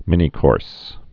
(mĭnē-kôrs)